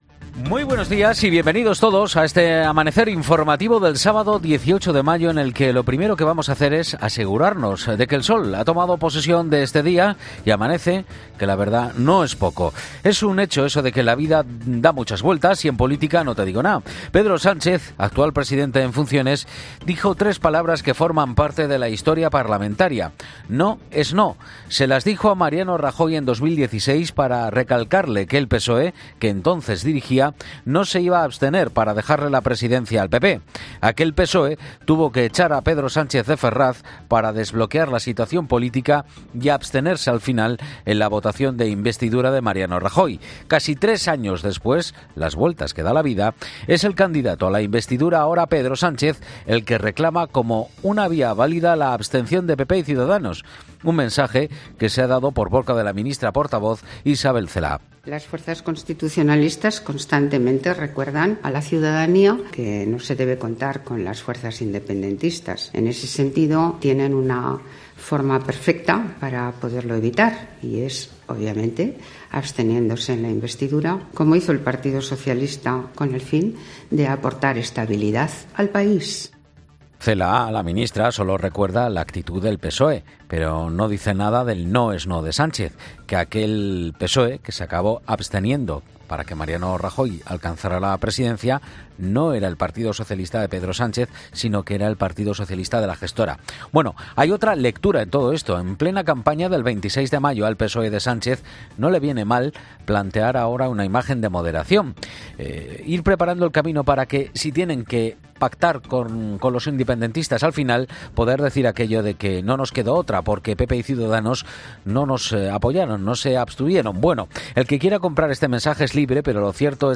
AUDIO: Monólogo